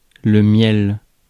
Ääntäminen
Ääntäminen France: IPA: [mjɛl] Haettu sana löytyi näillä lähdekielillä: ranska Käännös 1. мед {m} (med) Suku: m .